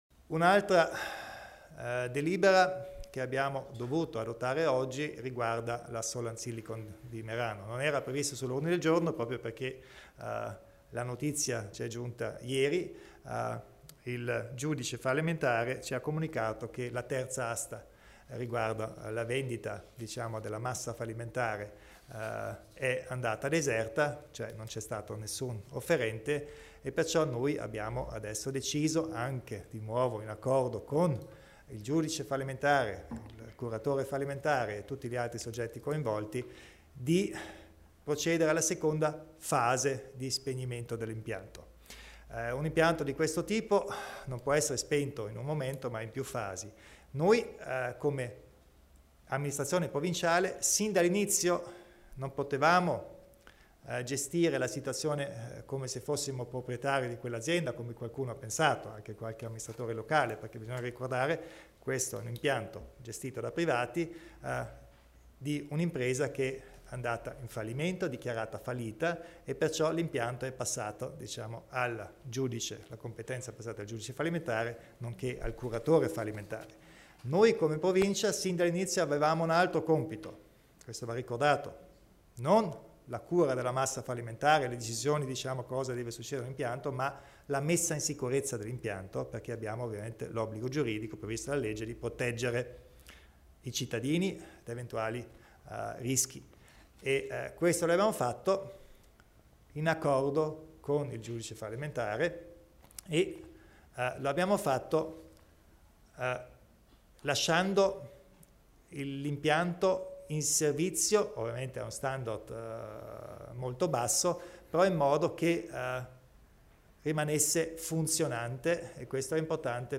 Il Presidente Kompatscher spiega il futuro della Solland Silicon